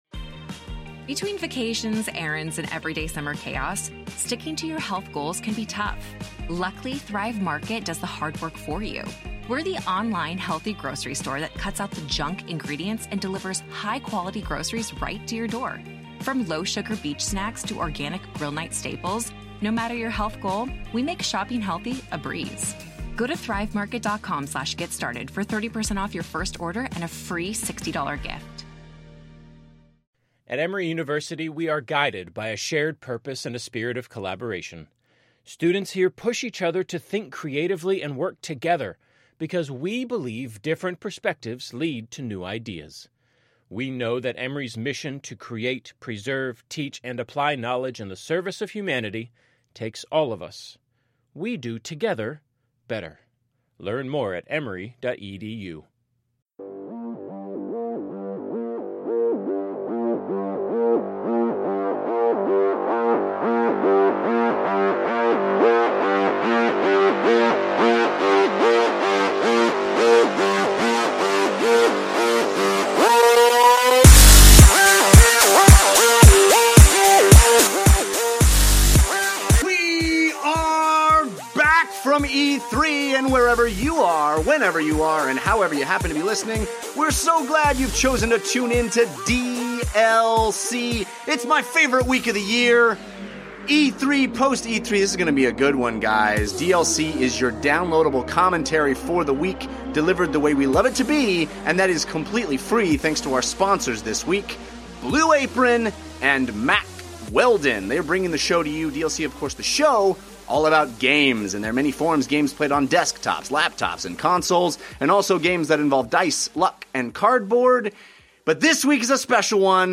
In this special episode the guys talk about all of the biggest games of the show, with their own hands-on impressions. Games include Spider-Man, Mario Odyssey, Days Gone, God of War, Star Wars Battlefront 2, Shadow of War, Beyond Good & Evil 2, Mario + Rabbids, Sea of Thieves, and so much more!